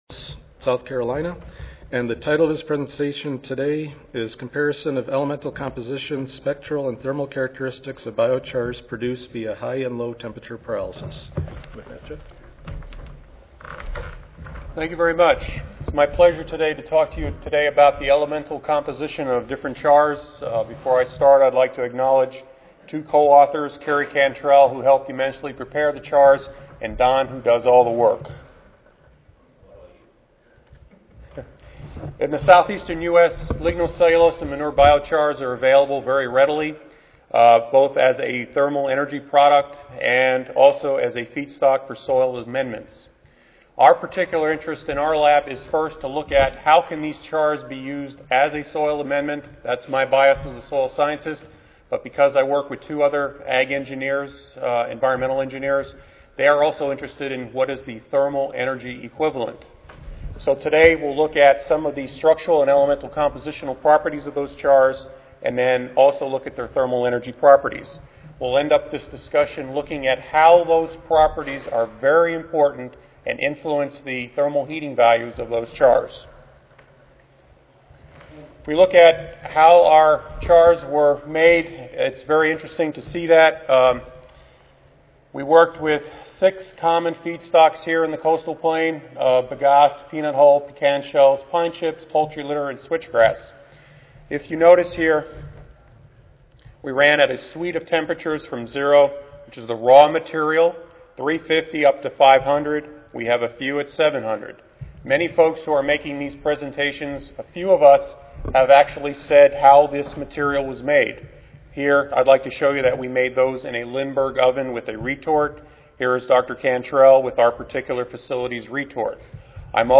ASA Section: Environmental Quality Session: Biochar: Environmental Uses (ASA, CSSA and SSSA Annual Meetings (San Antonio, TX - Oct. 16-19, 2011))
USDA-ARS Recorded Presentation Audio File